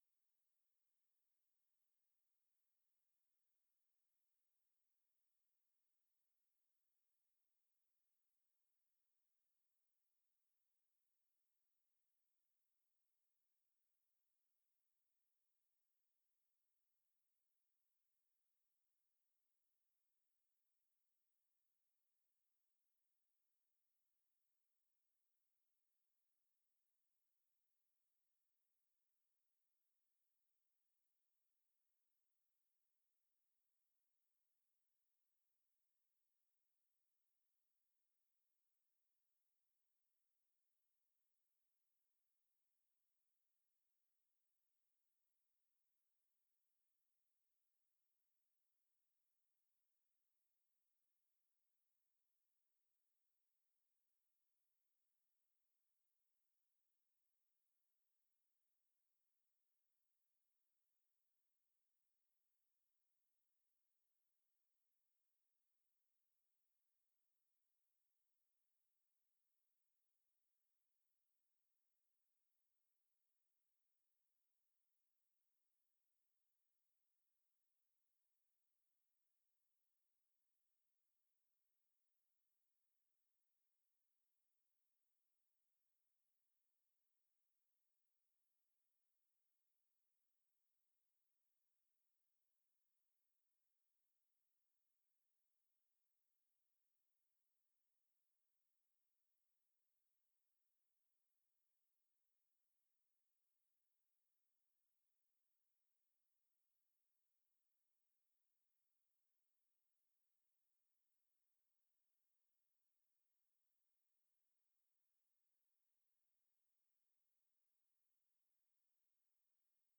יום 3 - הקלטה 6 - צהרים - מדיטציה מונחית